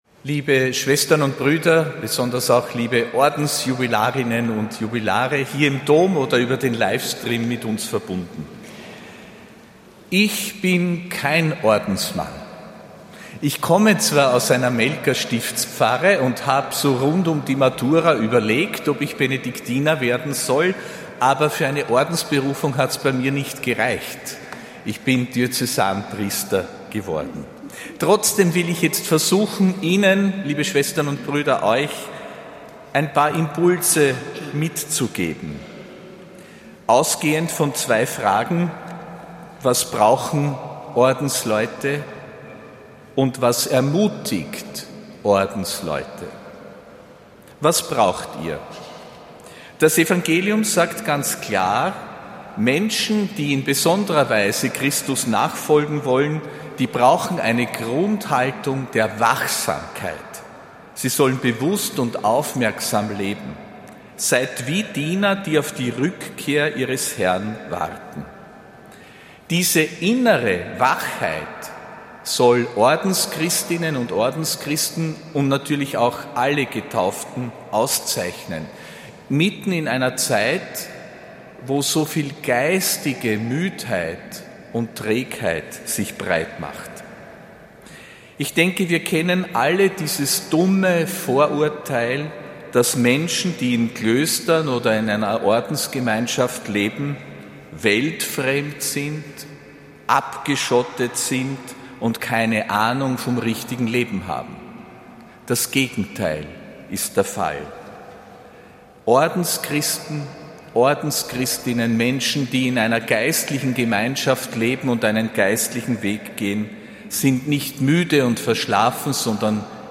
Predigt von Erzbischof Josef Grünwidl bei der Vesper zum Tag des geweihten Lebens, am 30. Jänner 2026.